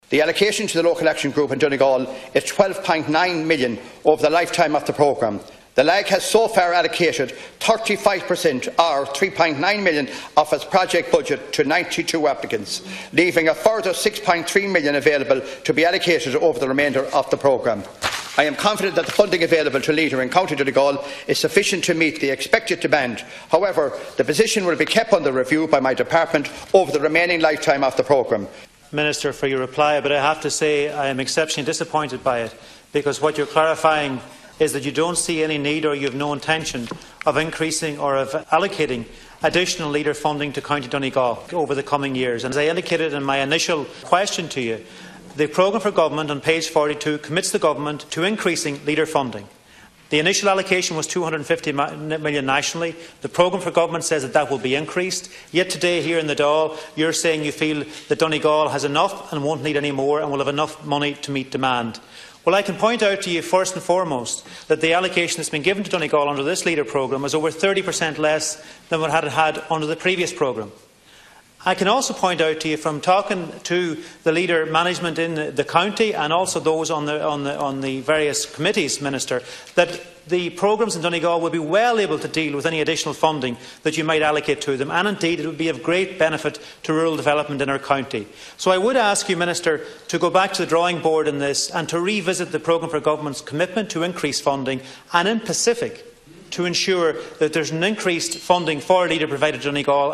Donegal Deputy Charlie McConalogue told Minister Ring that he must review his decision: